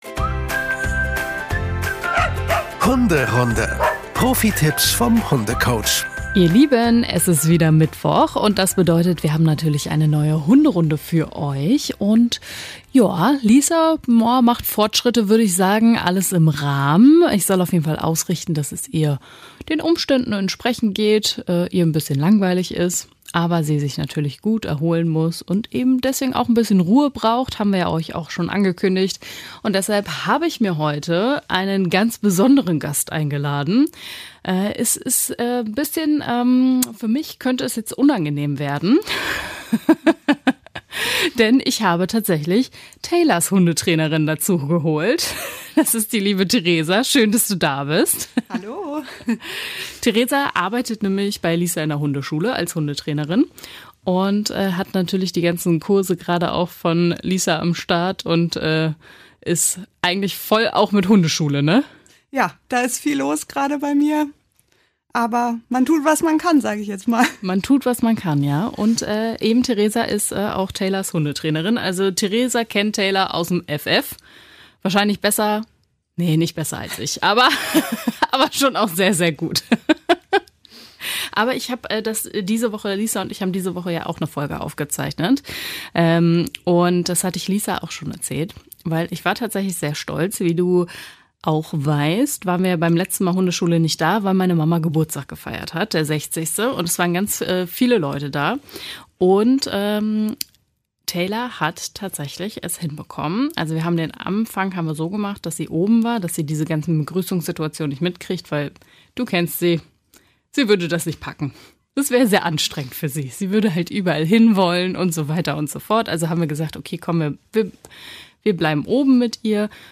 Tierpathologin zu Gast